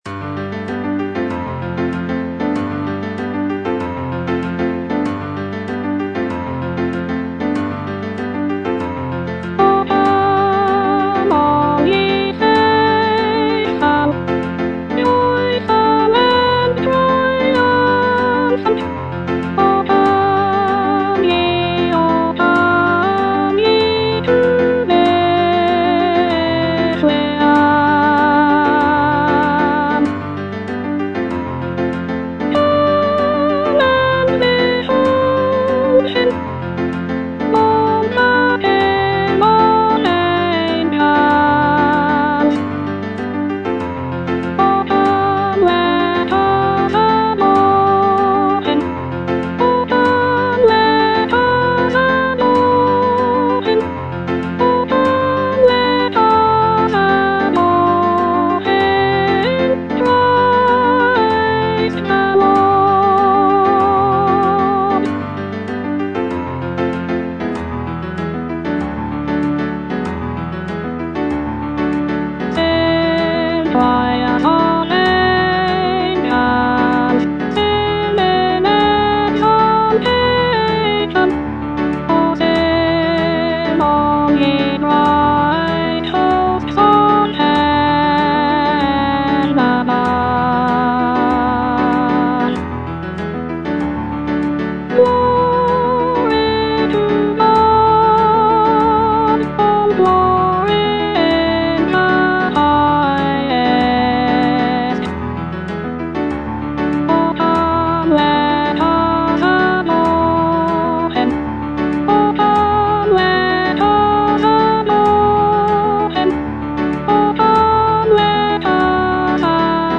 Soprano (Voice with metronome)
Christmas carol